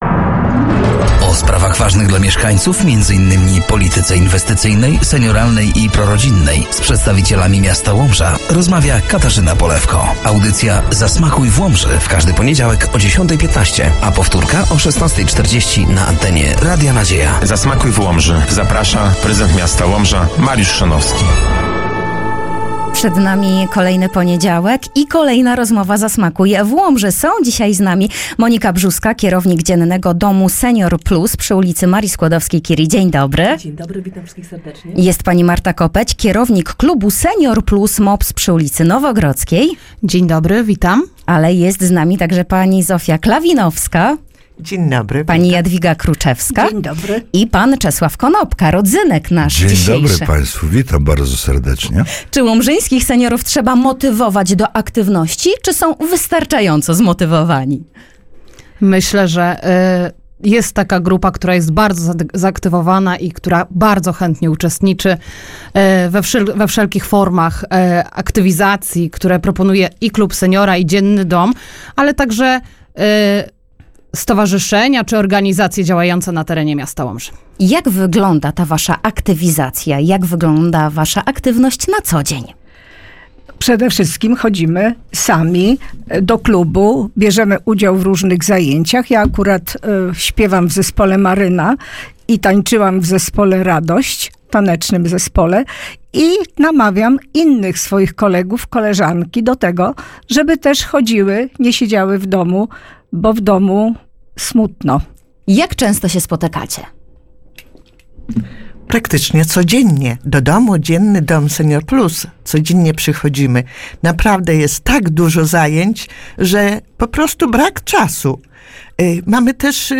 Audycja „Zasmakuj w Łomży”, w każdy poniedziałek o 10.15 na antenie Radia Nadzieja.